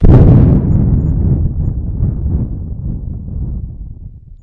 Thunders » Thunder16
描述：Quite realistic thunder sounds. I've recorded this by blowing into the microphone.
标签： Loud Storm Weather Lightning Thunderstorm Thunder
声道立体声